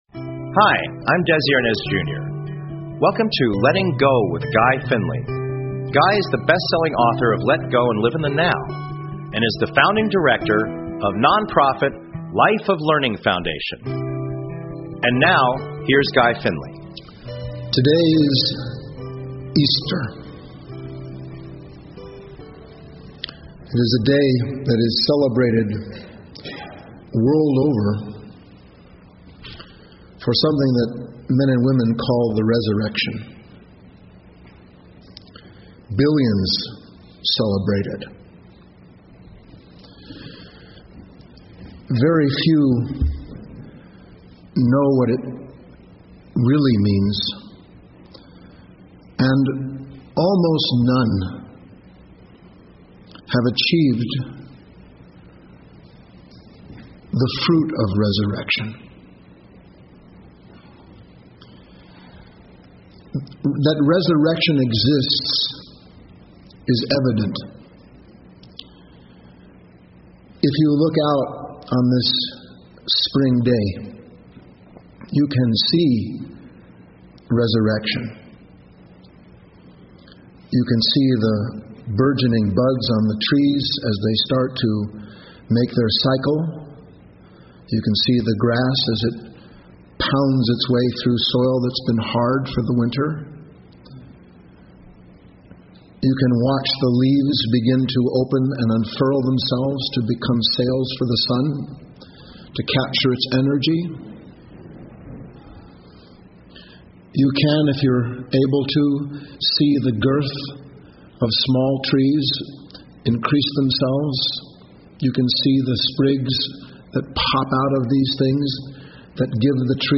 Talk Show Episode, Audio Podcast, Letting_Go_with_Guy_Finley and Courtesy of BBS Radio on , show guests , about , categorized as
Subscribe Talk Show Letting Go with Guy Finley Show Host Guy Finley GUY FINLEY’s encouraging and accessible message is one of the true bright lights in our world today.